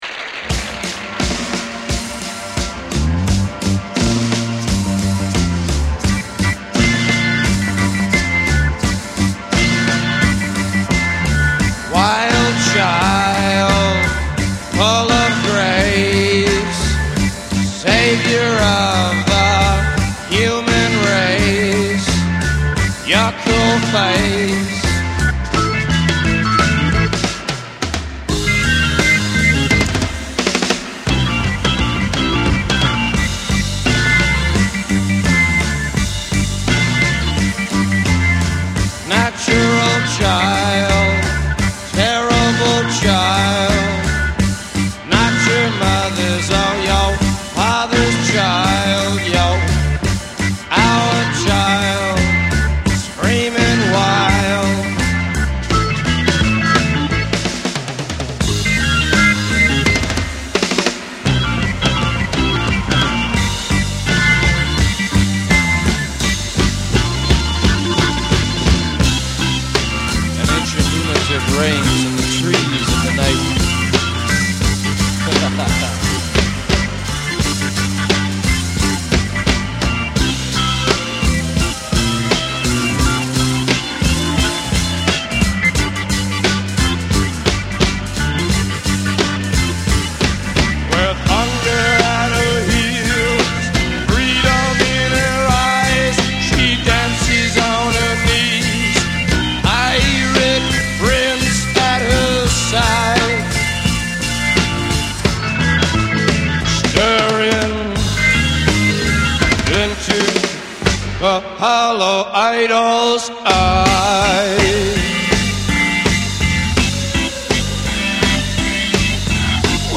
enregistré à N.Y.C
l'une des rares version live de ce titre